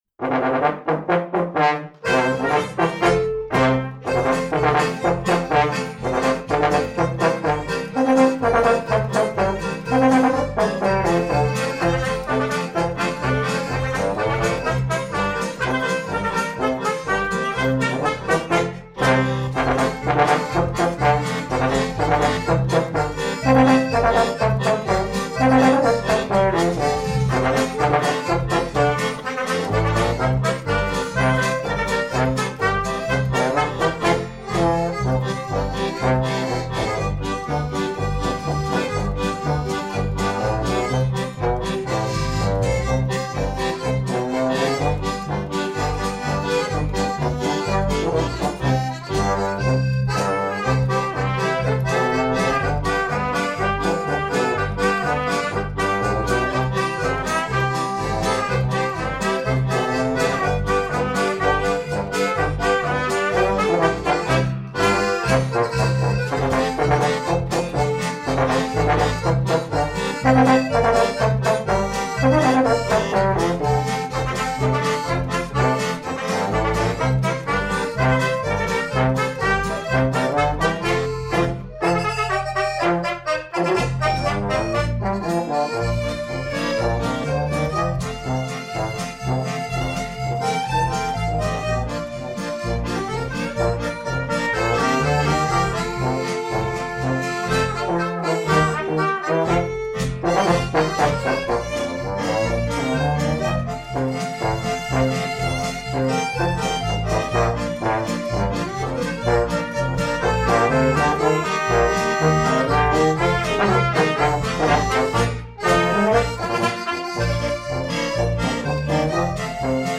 Proberaumaufnahme: Auf der Autobahn
auf-der-autobahn-probenaufnahme.mp3